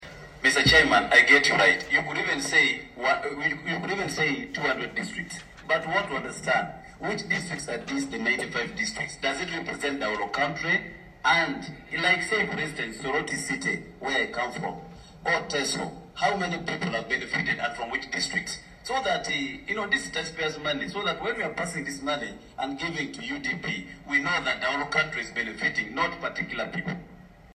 Members of Parliament on the Committee of National Economy concerned about distribution of the Uganda Development Bank (UDB) financing as the government seeks a US$275 million loan to recapitalise the bank.
Hon. Jonathan Ebwalu (NRM, Soroti West Division) questioned whether the reported 95 districts truly represented the entire country.